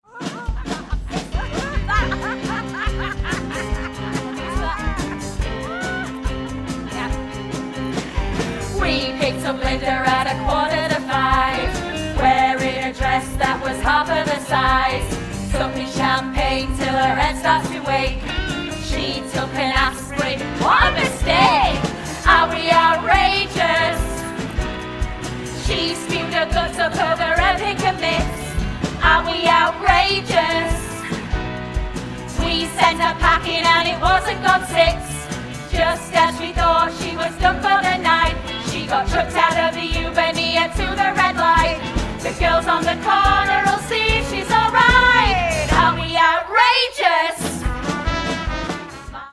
Demos